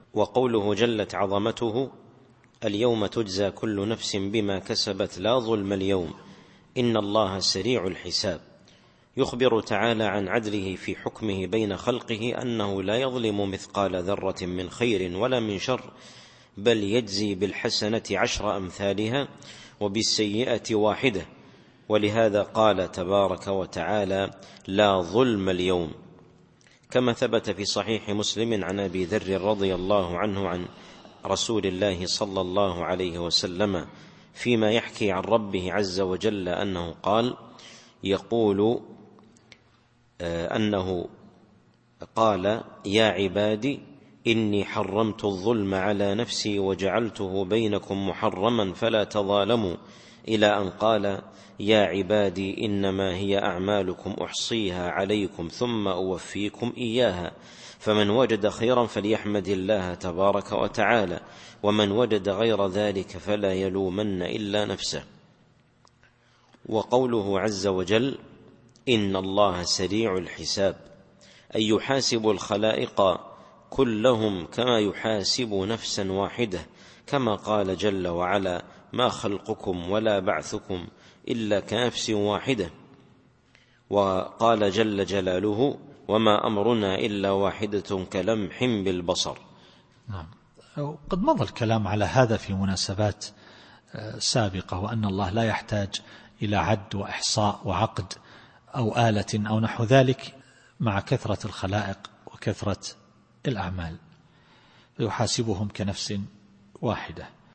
التفسير الصوتي [غافر / 17]